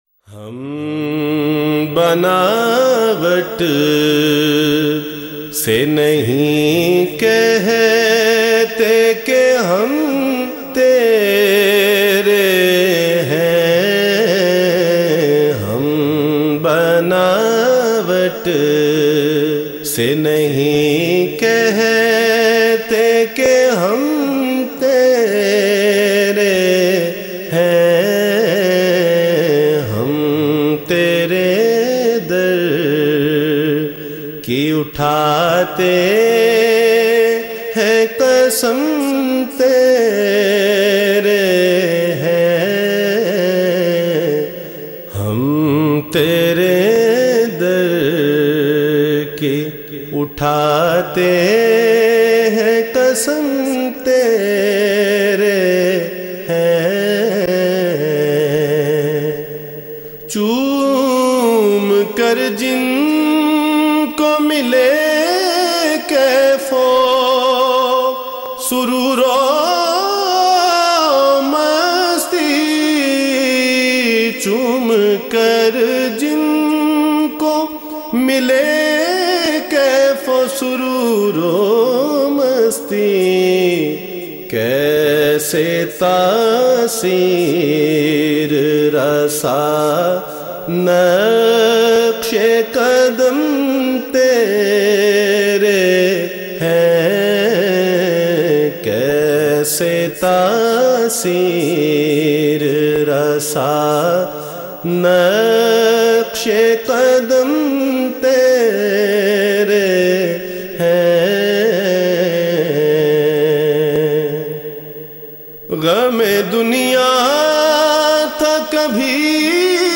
in a Heart-Touching Voice